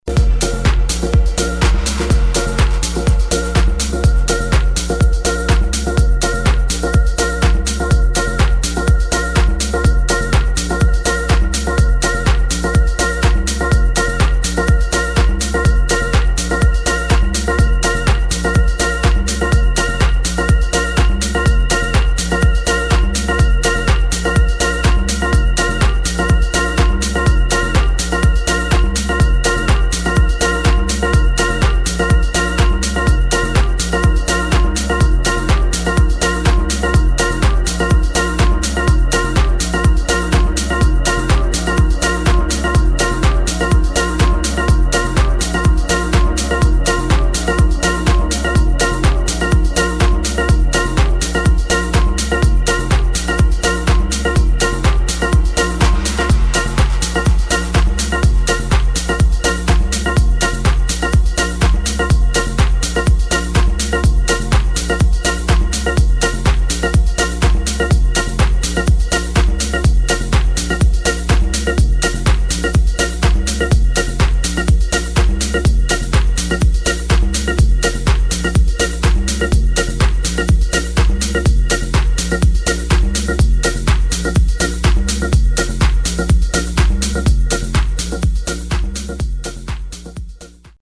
[ TECHNO / DEEP HOUSE ]